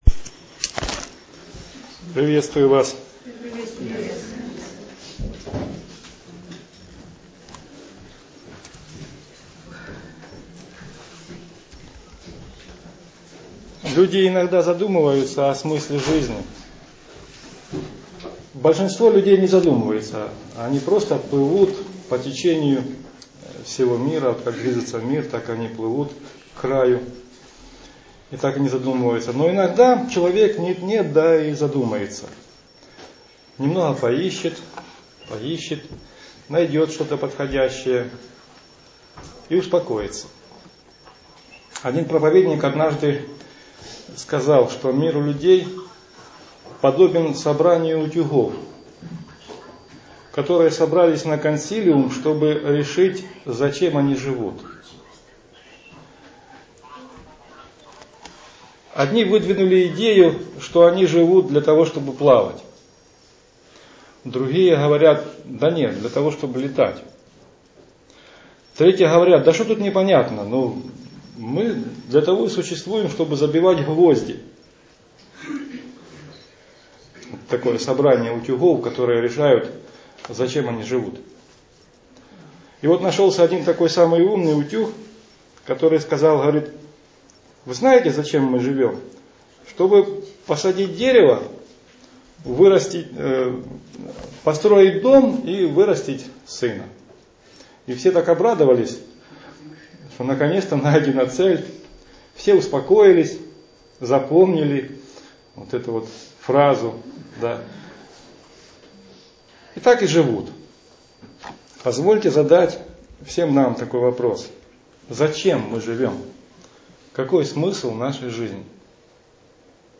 Итак, зачем мы живём? Аудио-проповедь.